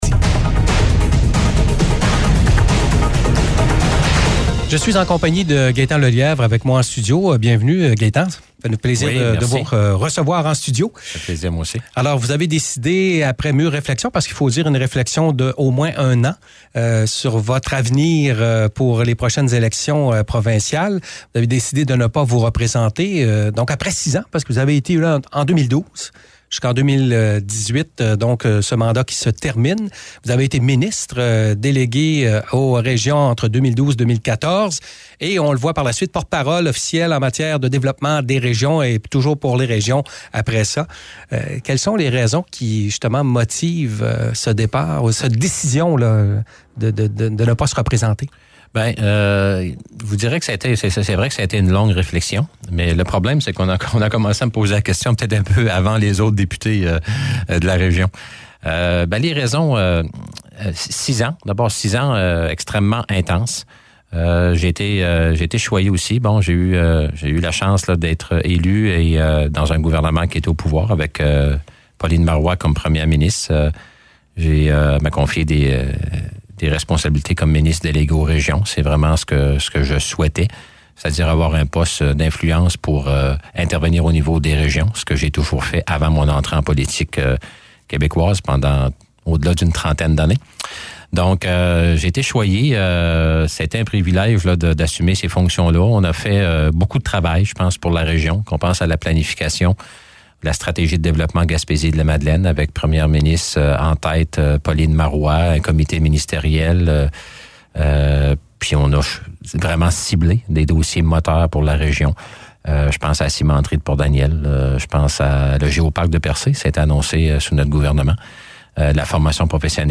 Entrevue avec Gaétan Lelièvre partie 1: